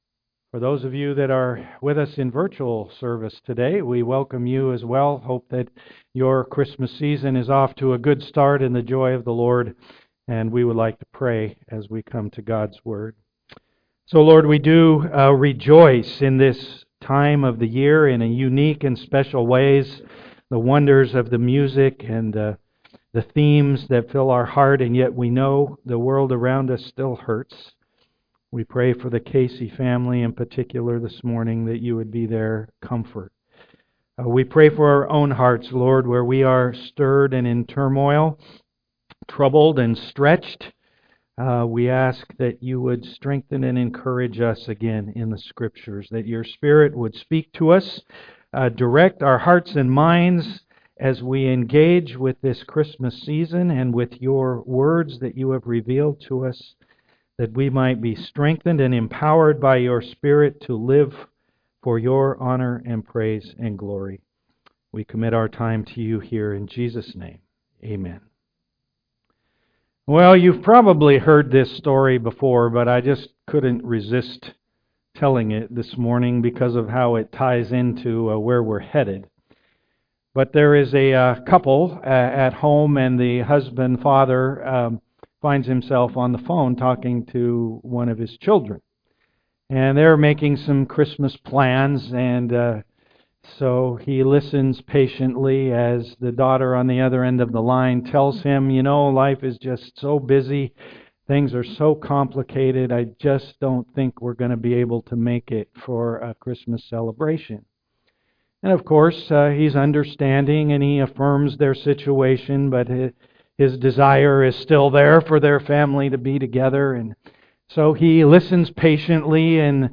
various Service Type: am worship The plan for Jesus' coming was long planned for and with very specific reason.